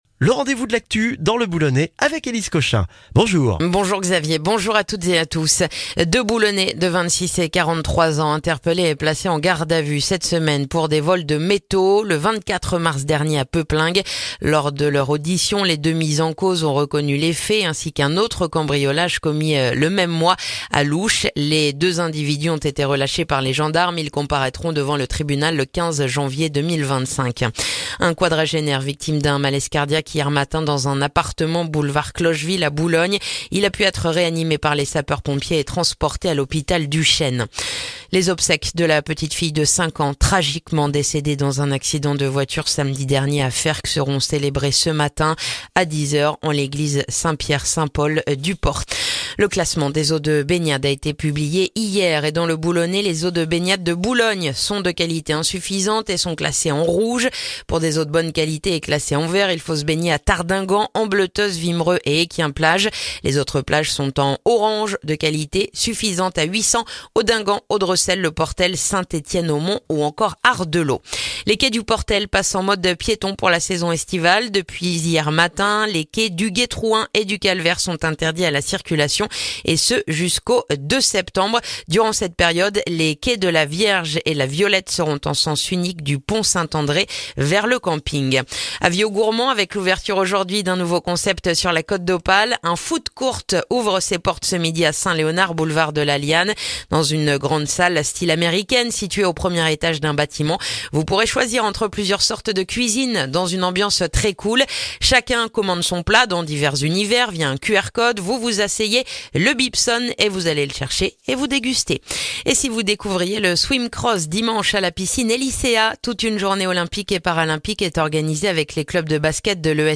Le journal du vendredi 21 juin dans le boulonnais